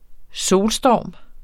Udtale [ ˈsoːlˌsdɒˀm ]